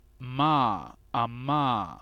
[m]
English mime
Bilabial_nasal.ogg.mp3